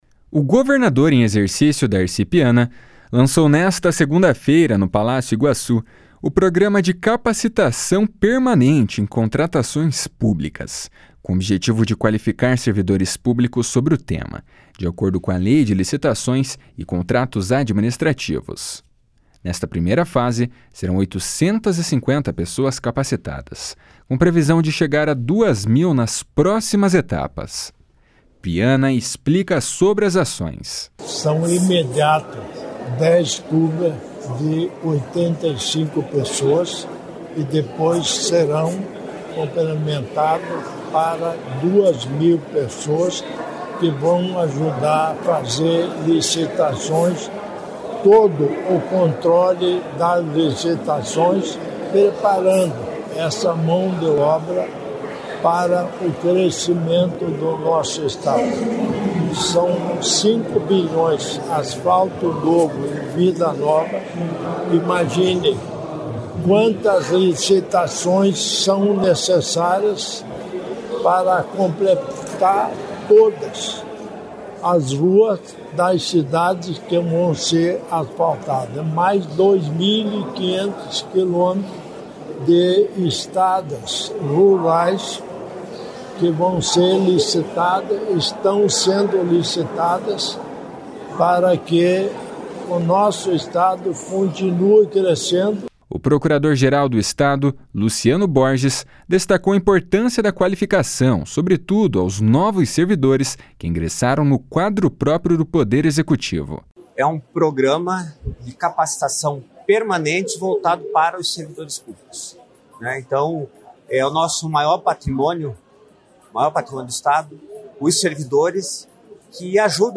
Nesta primeira fase, serão 850 pessoas capacitadas, com previsão de chegar a 2 mil nas próximas etapas. Piana explica sobre as ações.
O procurador-geral do Estado, Luciano Borges, destacou a importância da qualificação, sobretudo aos novos servidores que ingressaram no Quadro Próprio do Poder Executivo.
O secretário de Estado da Administração e da Previdência, Luizão Goulart, explica sobre o funcionamento da secretaria.